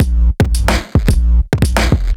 Index of /musicradar/off-the-grid-samples/110bpm
OTG_Kit8_Wonk_110b.wav